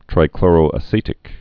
(trī-klôrō-ə-sētĭk)